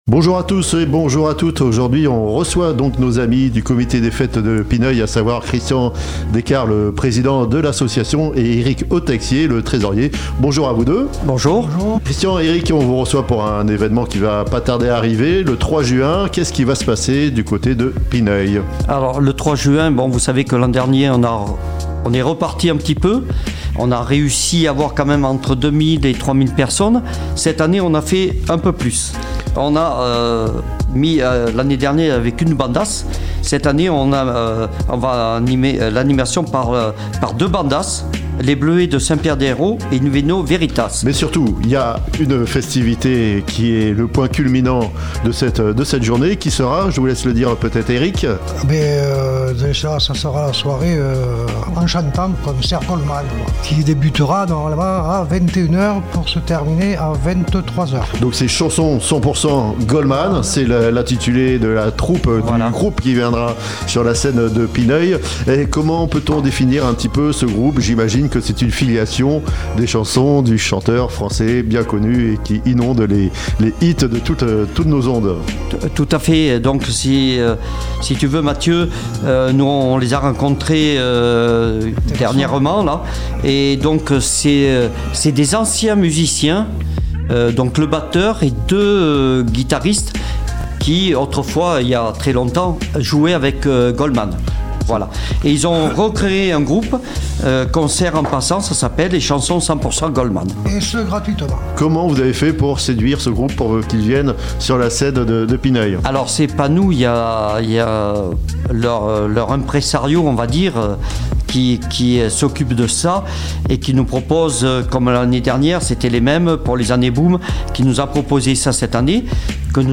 3. Les invités sur Radio Grand "R"